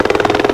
HELICPTR.WAV